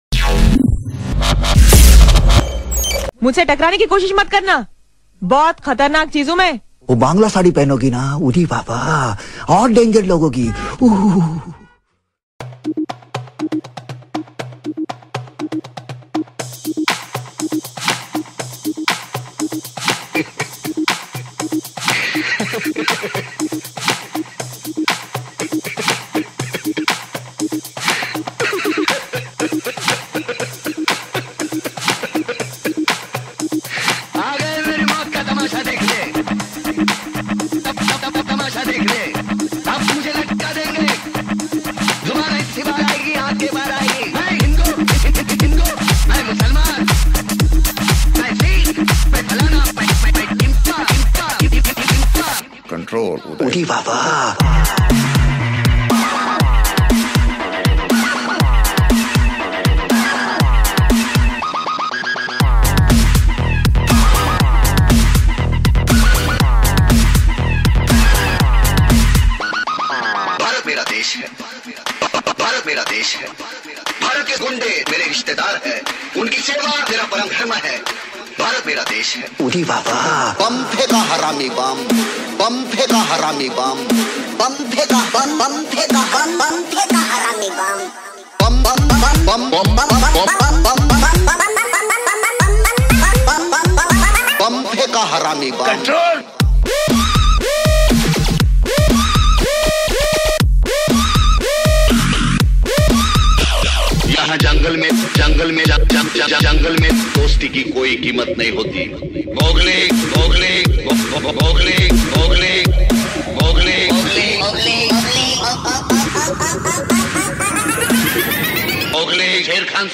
DJ Remix Mp3 Songs > DJ Dubstep Songs